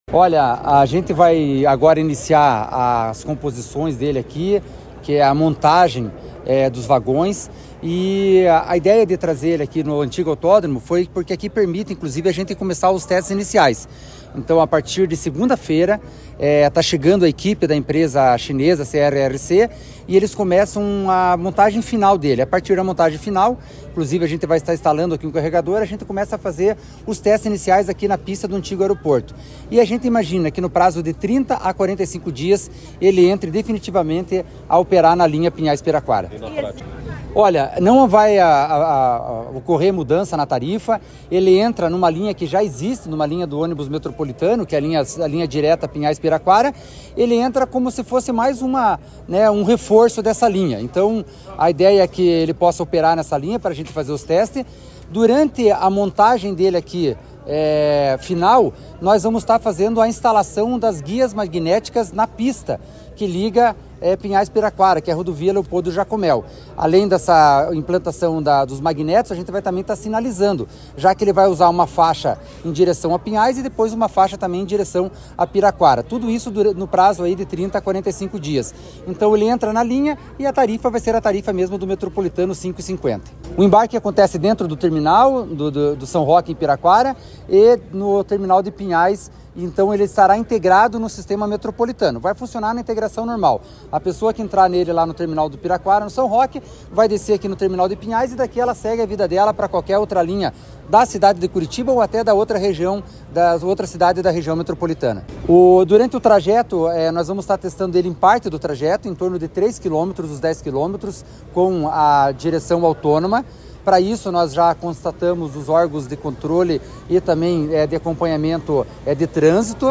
Sonora do diretor-presidente da Amep, Gilson Santos, sobre o Bonde Urbano Digital